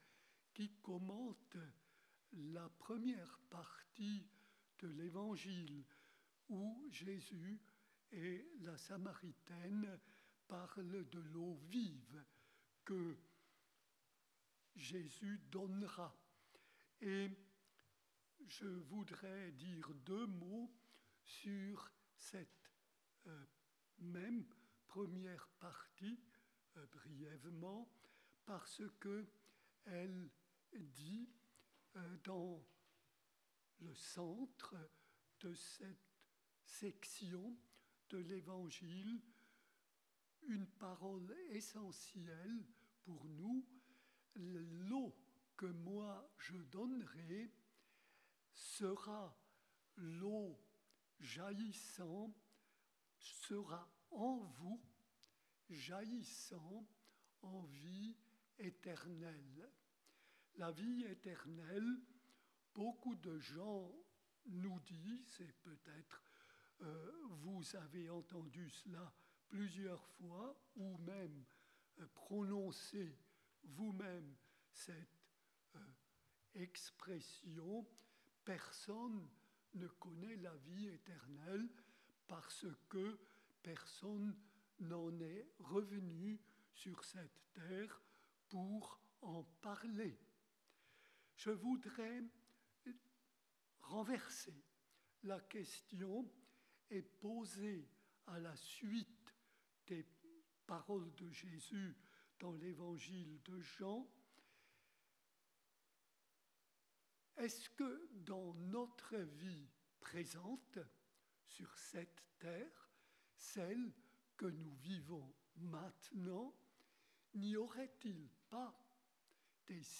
Pour découvrir son homllie, nous vous invitons à écouter un enregistrement en direct.